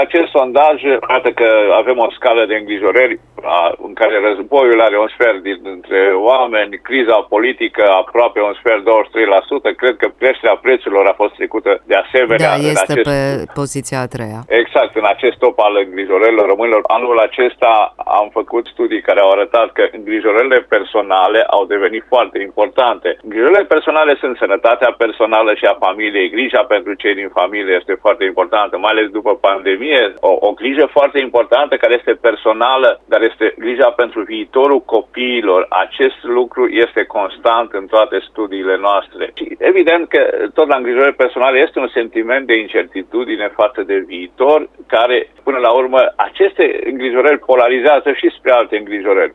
Sociologul Vasile Sebastian Dâncu a fost invitat la Radio Cluj și a vorbit despre principalele îngrijorări ale românilor: